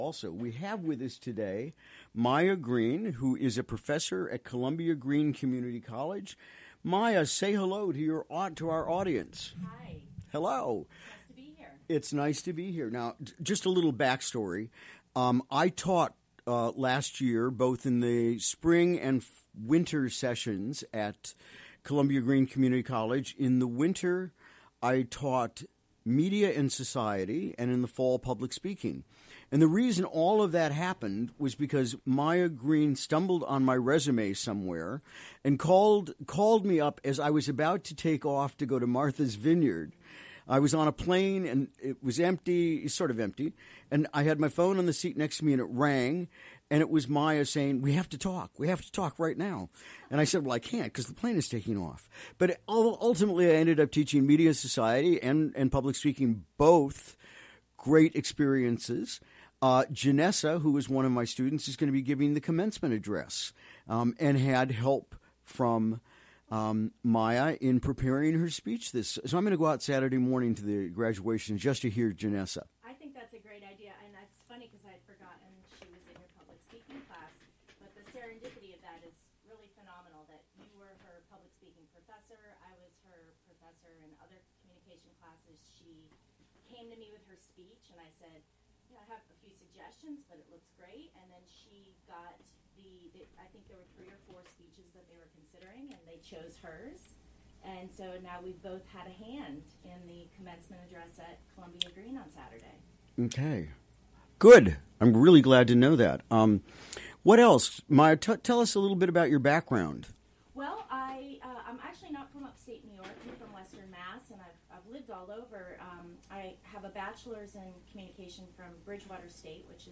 Recorded during the WGXC Morning Show on Wednesday, May 10.